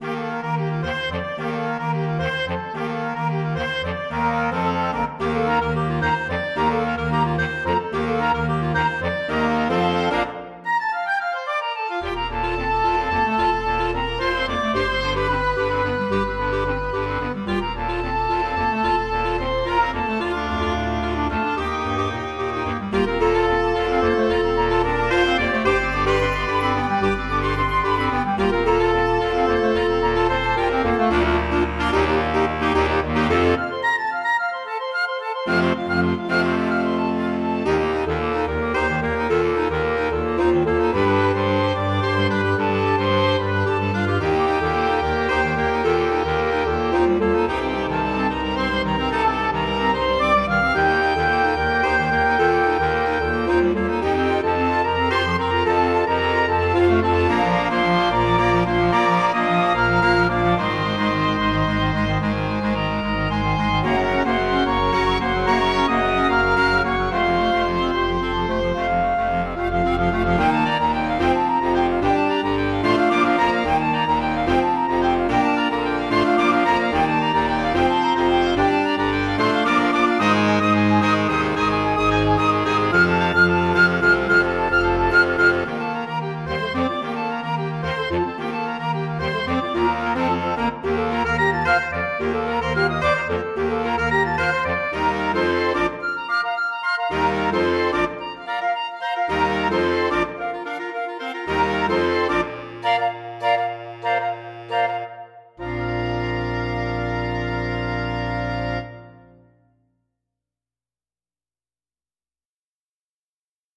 Voicing: Woodwind Ensemble